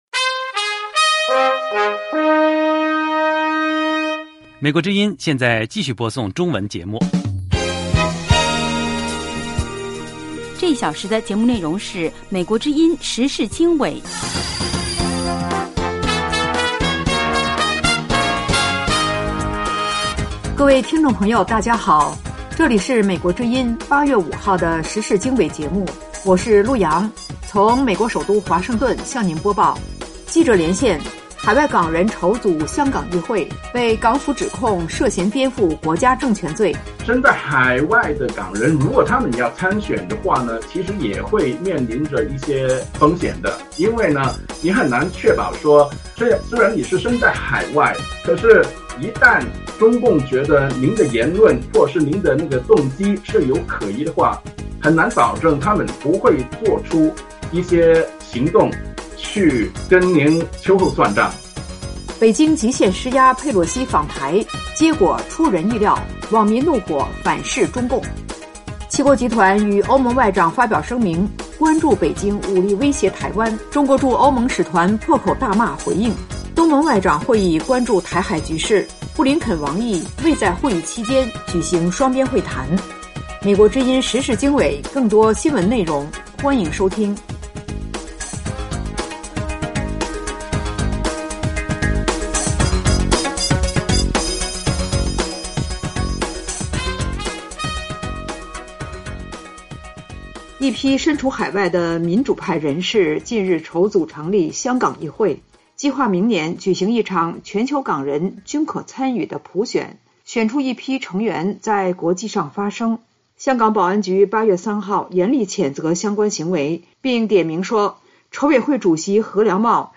时事经纬(2022年8月5日)：1/记者连线：海外港人筹组“香港议会” 被港府指控涉嫌颠覆国家政权罪。2/北京极限施压佩洛西访台，结果出人意料网民怒火反噬中共。